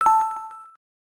adds missing several sound effects
GUI save choice.ogg